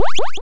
bfxr_teleporthigh.wav